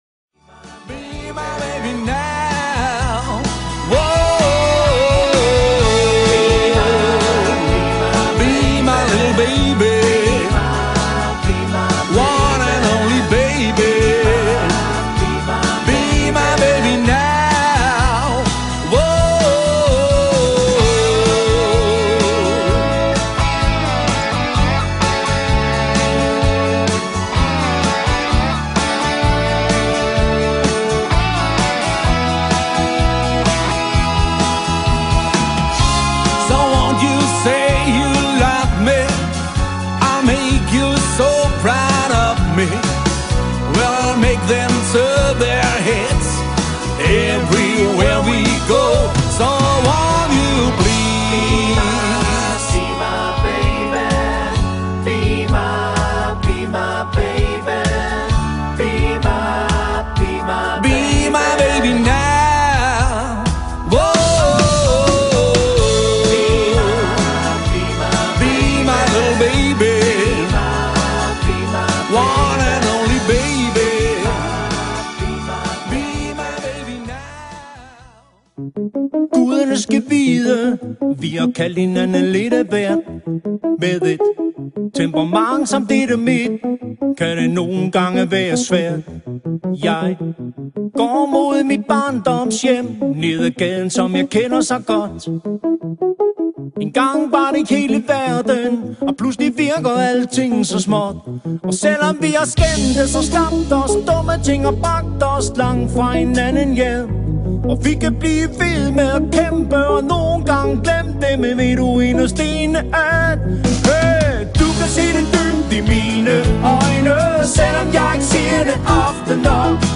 fed dansemusik